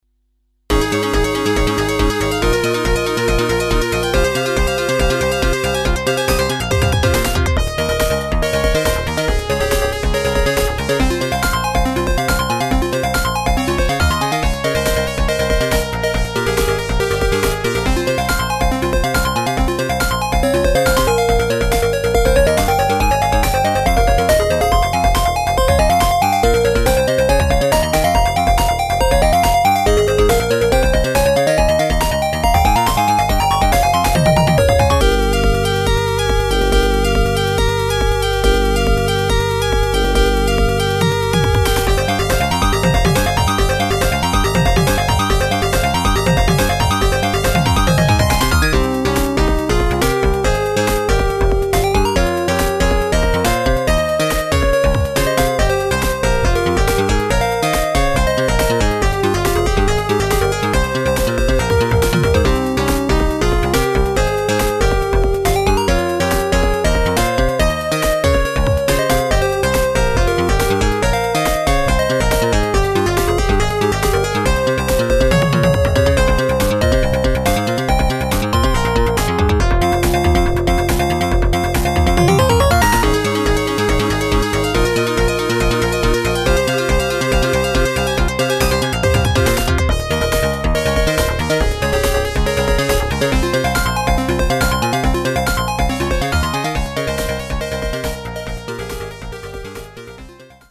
「ＦＭ音源」
というのは嘘で、ＰＣ−９８２１からサルベージしてきたＦＭ音源＋ＳＳＧ音源の曲。